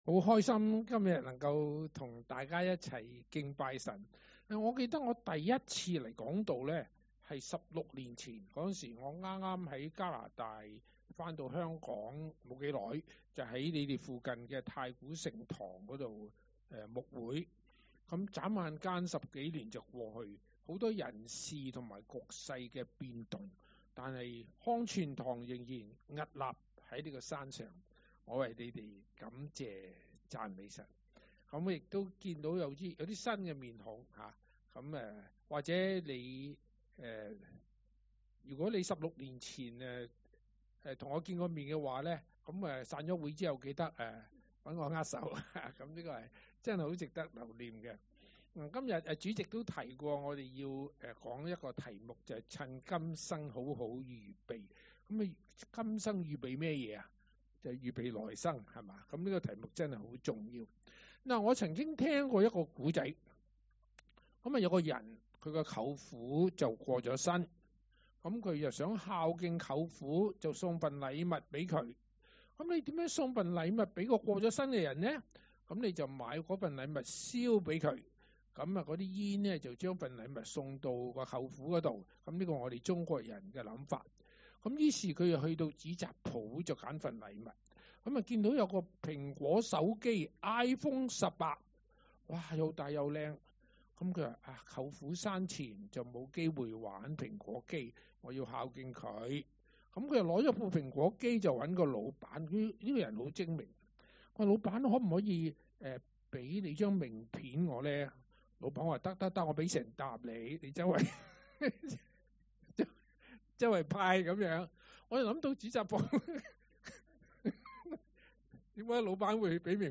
講道 ：趁今生好好預備